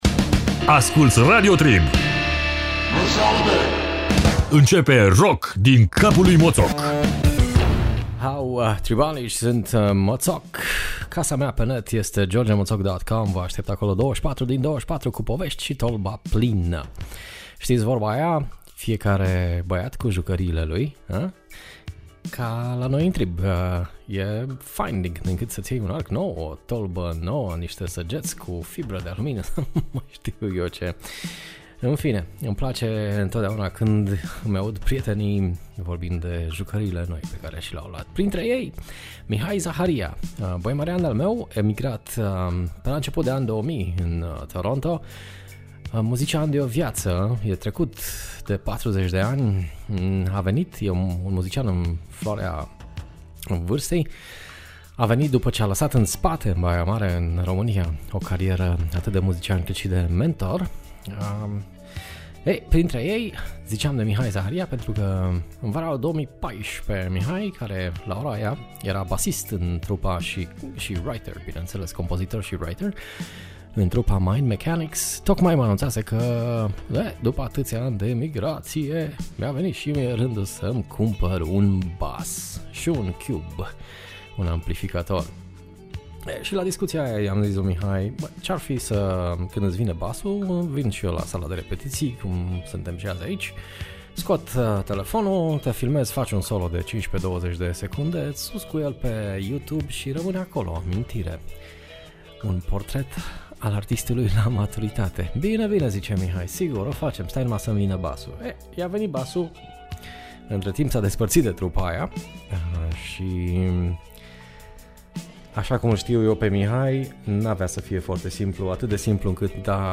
Emisiunea mea saptaminala la Radio Trib.